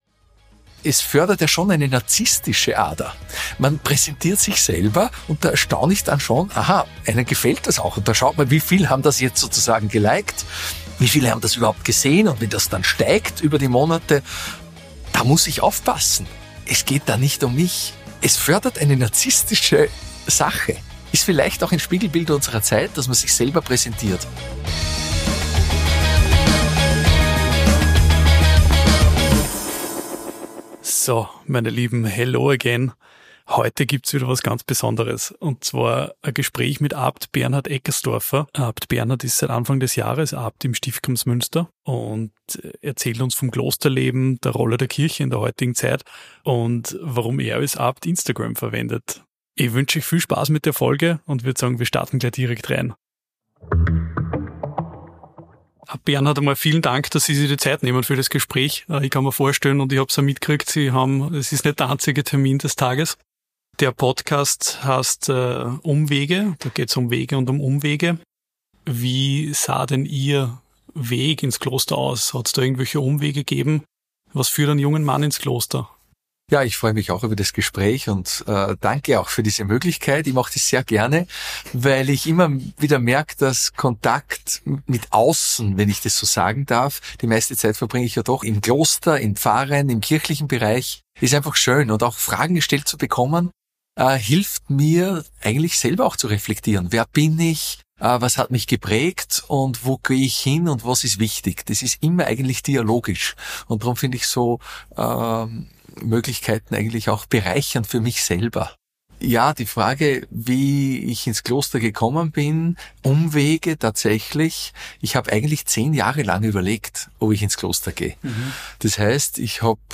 Abt Bernhard Eckerstorfer wurde Anfang 2025 für die nächsten 12 Jahre zum Abt des Stiftes Kremsmünster gewählt. Im Gespräch